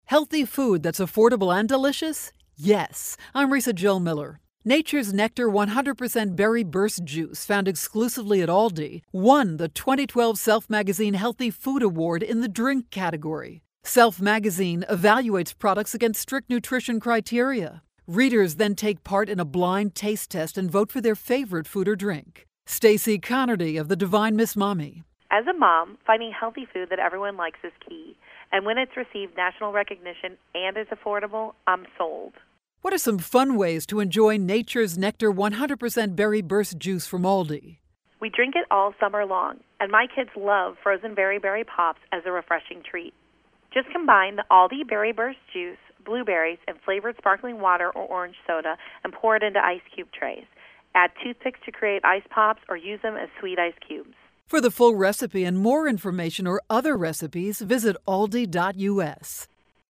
June 12, 2012Posted in: Audio News Release